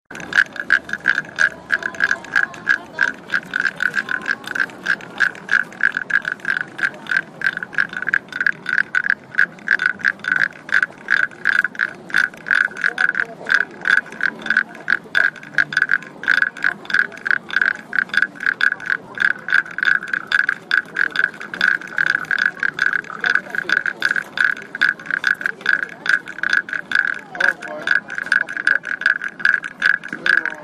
かえる タイ.mp3　タイのおもちゃ 口にくわえた棒を抜いて背中をこする